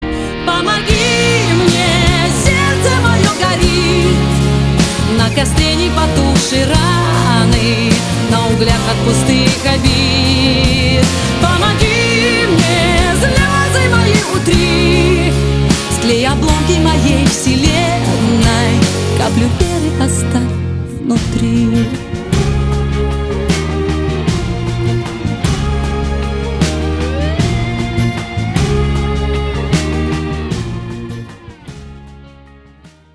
• Качество: 320, Stereo
громкие
женский вокал
саундтреки
Pop Rock
русский рок